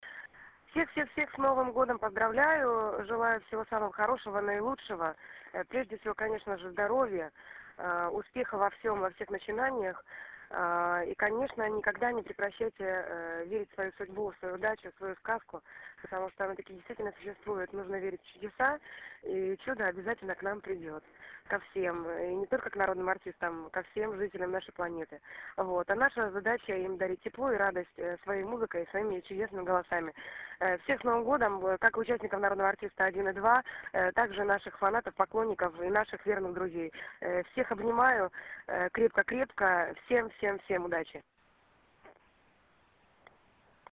ЭКСКЛЮЗИВНОЕ ПОЗДРАВЛЕНИЕ С НОВЫМ ГОДОМ